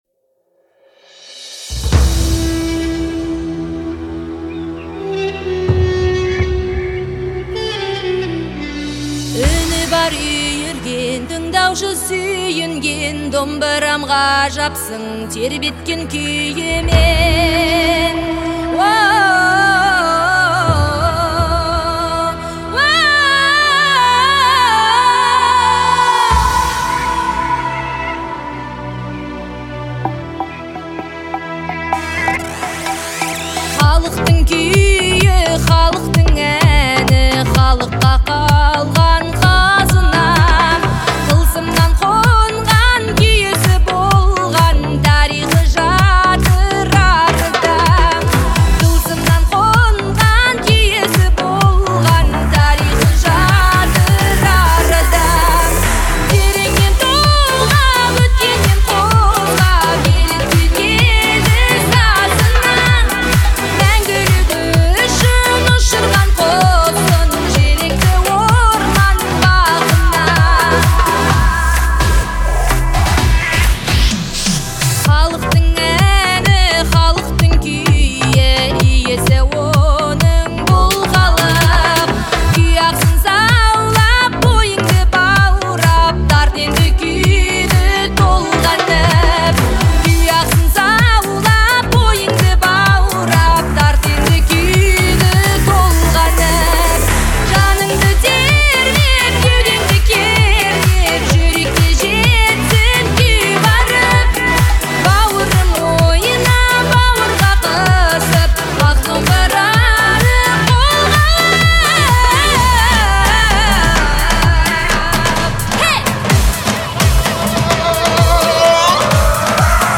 казахской исполнительницы